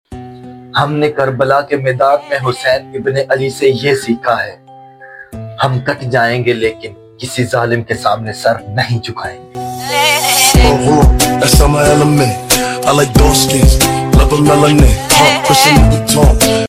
karachi Mushaira Marqa e Haq